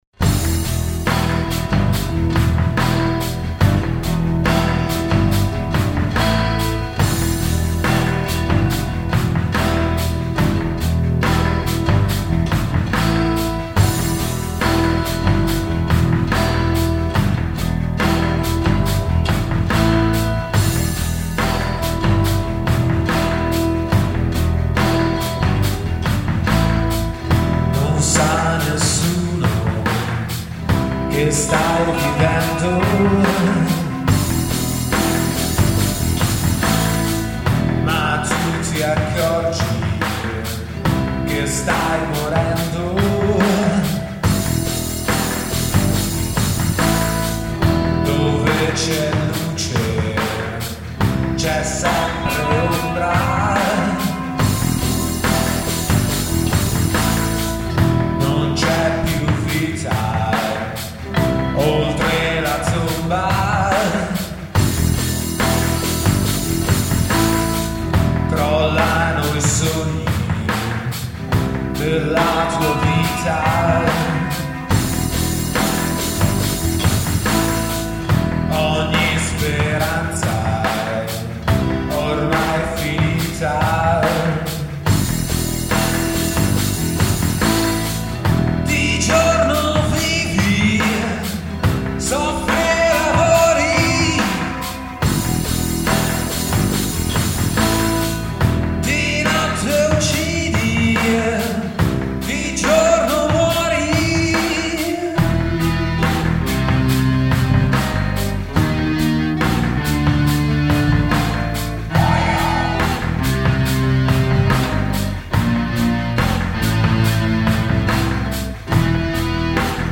Voci
Basso
Chitarre e programming